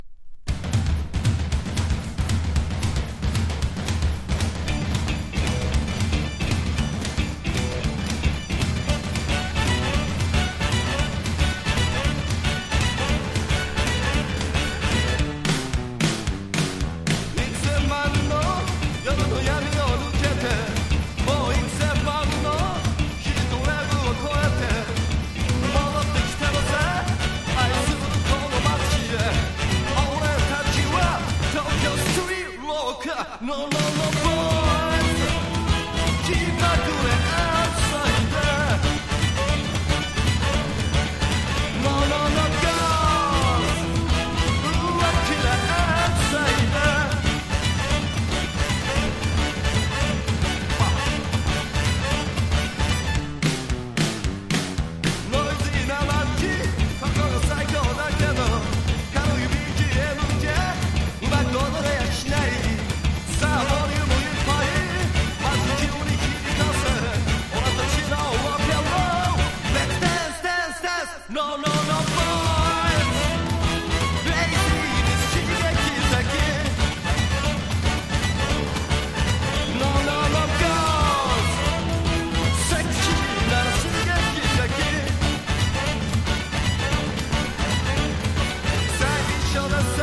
60-80’S ROCK# PUNK / HARDCORE